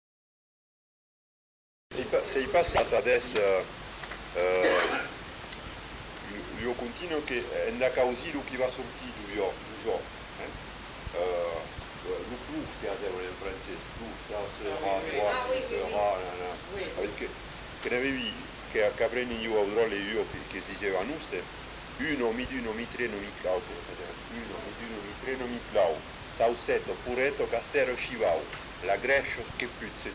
Proposition de comptines et formulettes par un des enquêteurs
Aire culturelle : Bazadais
Lieu : Bazas
Genre : parole